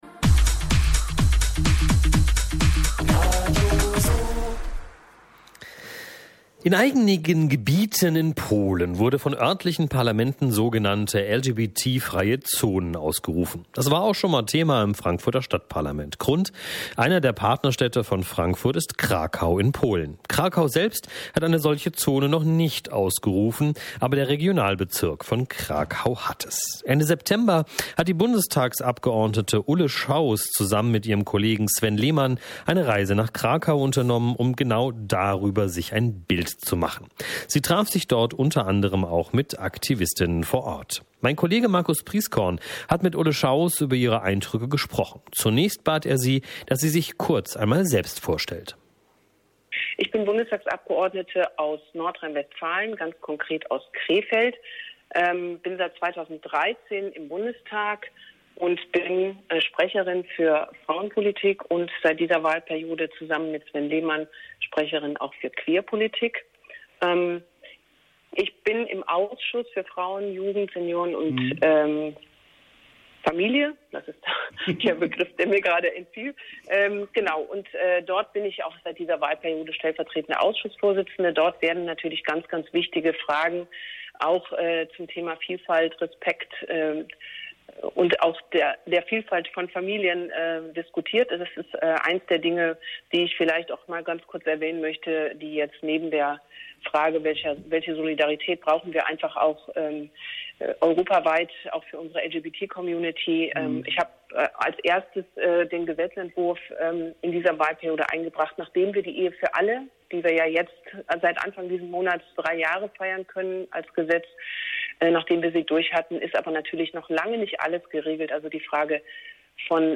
hat mit der Grünen-Bundestagsabgeordneten Ulle Schauws gesprochen